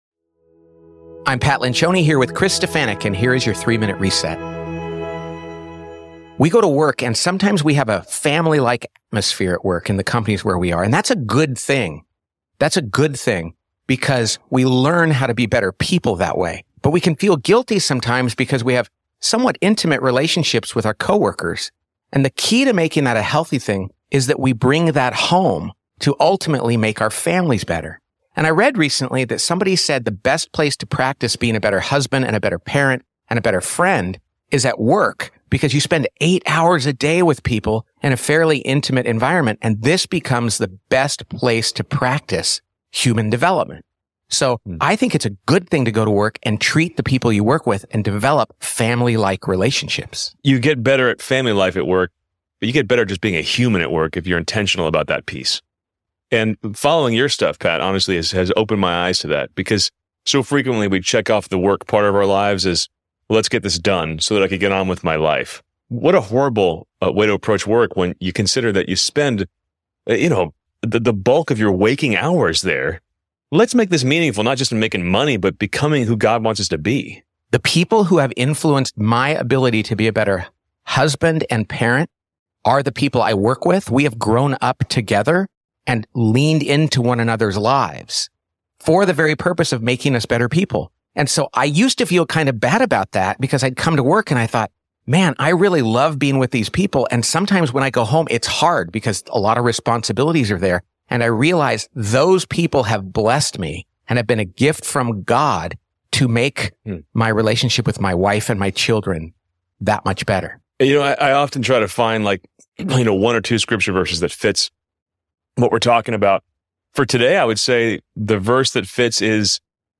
give a daily reflection for Christians in the workplace.